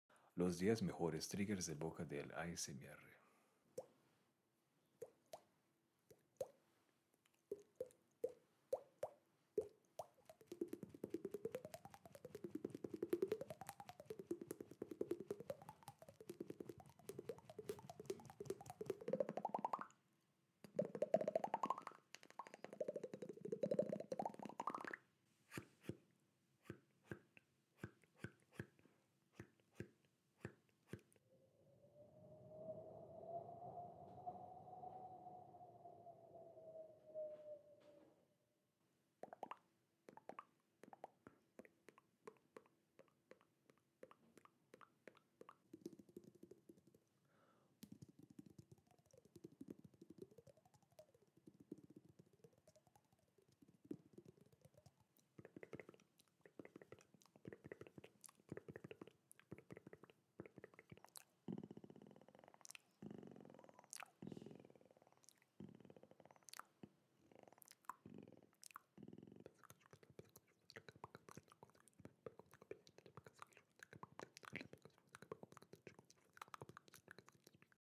ASMR's top 10 mouth sounds#asmrsonidos#asmrespañol#asmr_tingles#asmrsounds#mouthsounds sound effects free download